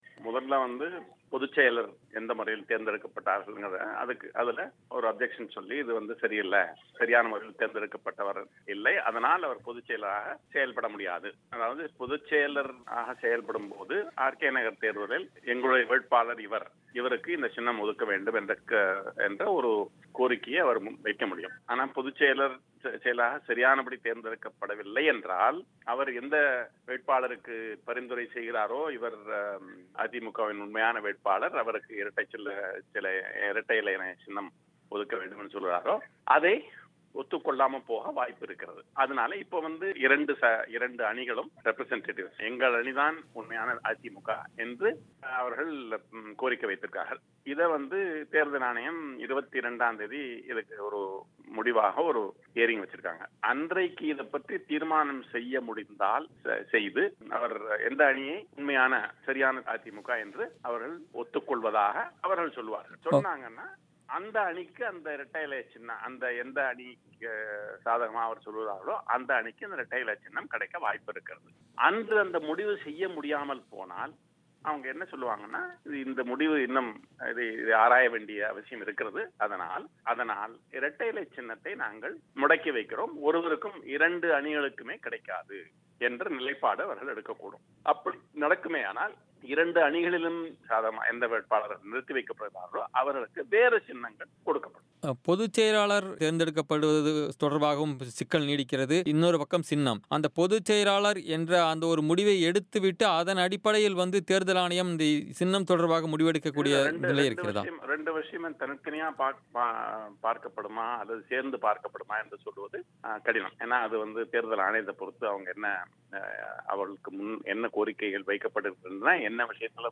இந்தியாவின் முன்னாள் தலைமை தேர்தல் ஆணையாளர் என்.கோபாலசுவாமி பேட்டி